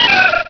Cri de Rosélia dans Pokémon Rubis et Saphir.